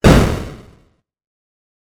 explo3.wav